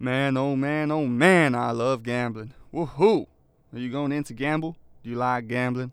Voice Lines
man oh man OH MAN i love gambling.wav